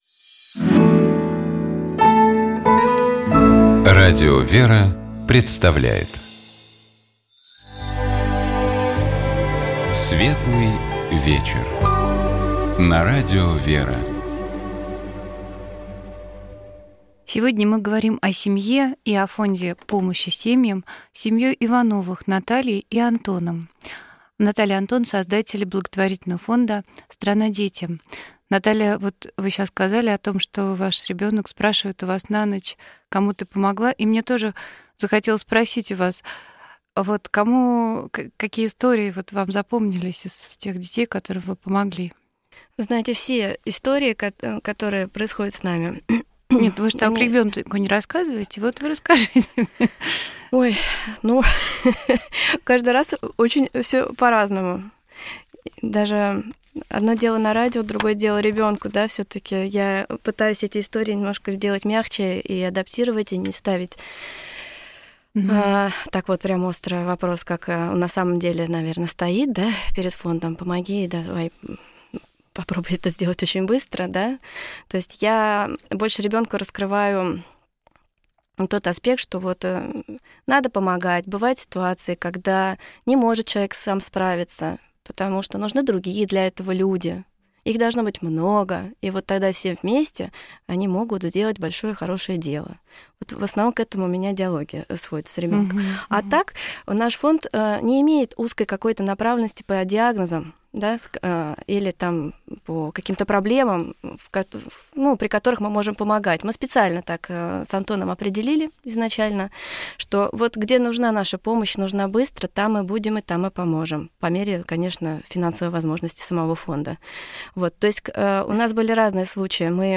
У нас в гостях были создатели благотворительного фонда «Страна детям».